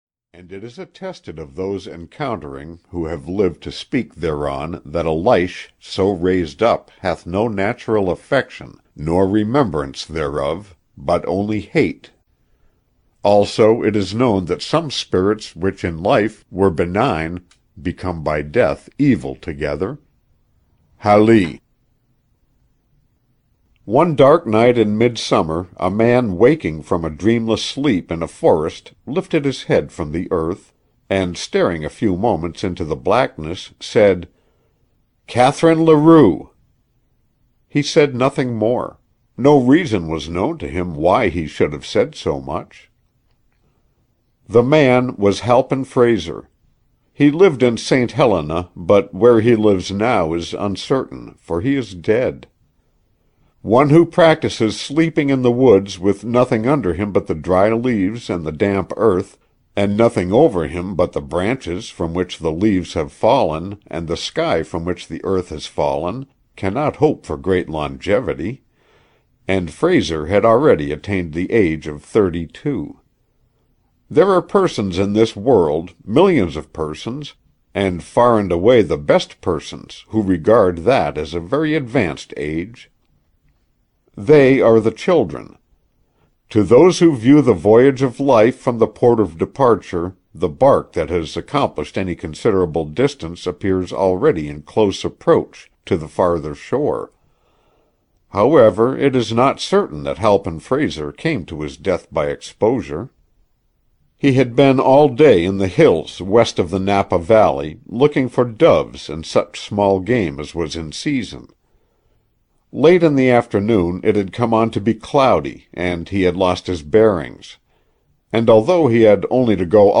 Can Such Things Be? (EN) audiokniha
Ukázka z knihy